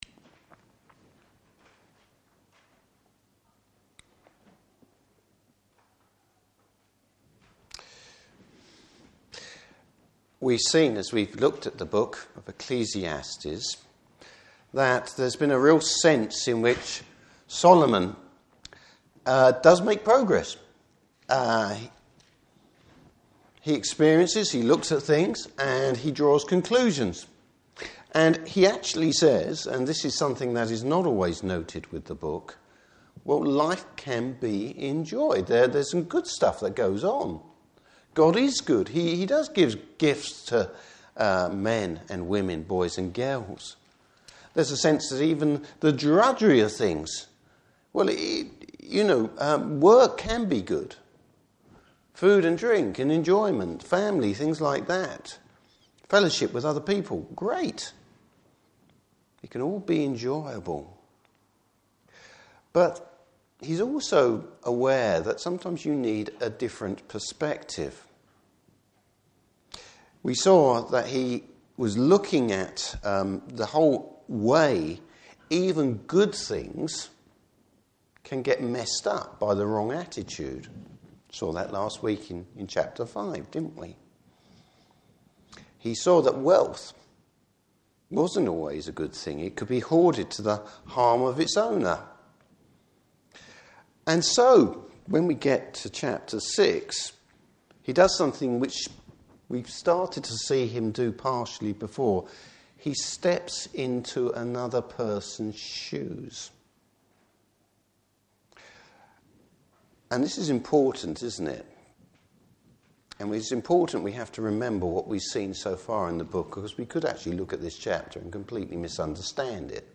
Service Type: Morning Service Bible Text: Ecclesiastes 6.